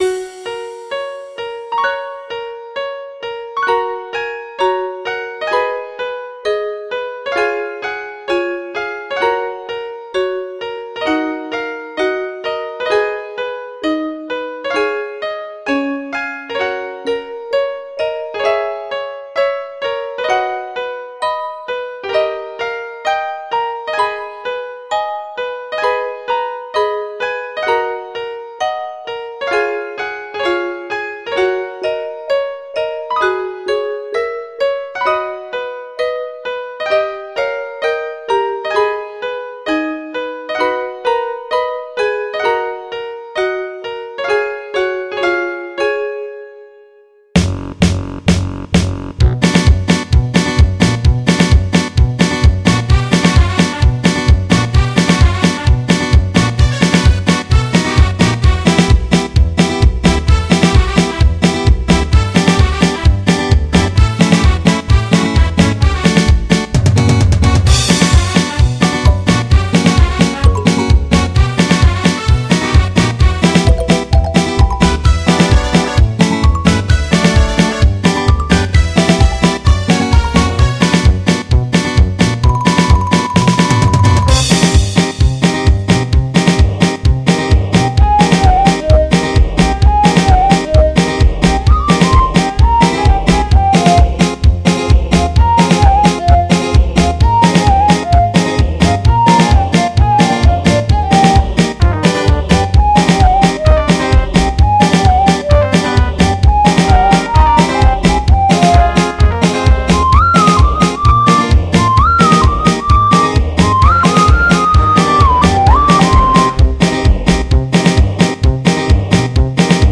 Мелодия из Кинофильма
лёгкое, ироничное и немного праздничное настроение